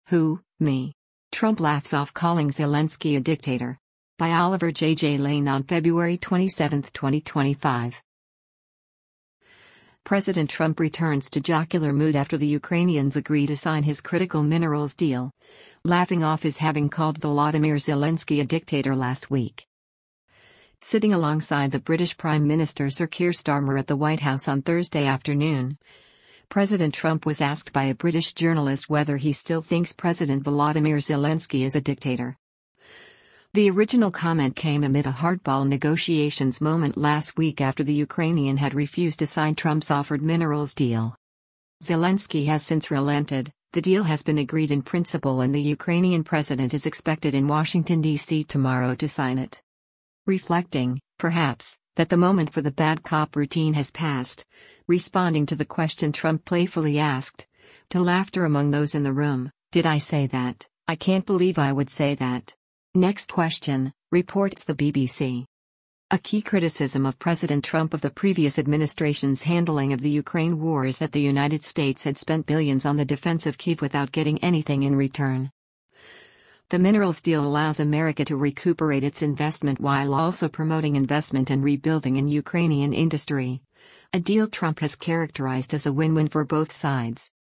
Reflecting, perhaps, that the moment for the bad cop routine has passed, responding to the question Trump playfully asked — to laughter among those in the room — “Did I say that, I can’t believe I would say that. Next question!”, reports the BBC.